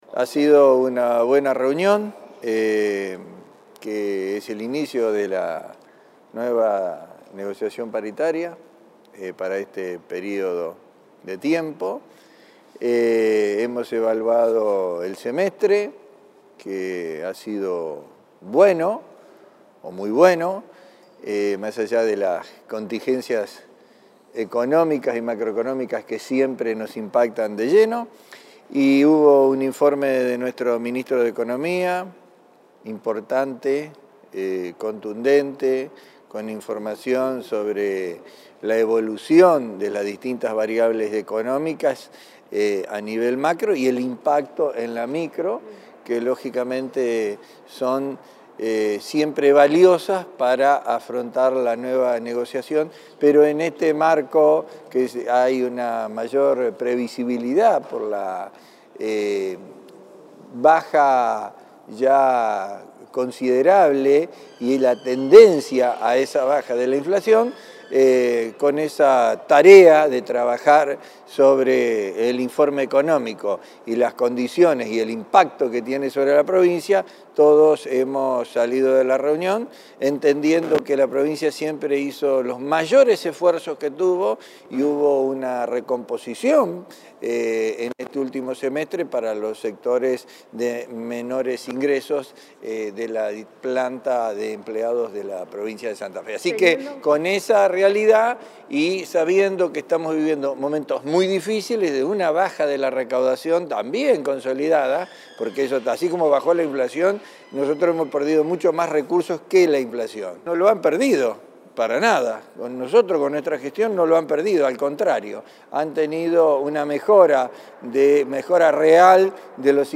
Declaraciones de los ministros Bastía y Olivares